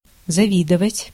Ääntäminen
Synonyymit jalouser Ääntäminen France: IPA: [ɑ̃.vje] Haettu sana löytyi näillä lähdekielillä: ranska Käännös Ääninäyte 1. завидовать {f} (zavidovat) Määritelmät Verbit Désirer pour soi les avantages d’ autrui .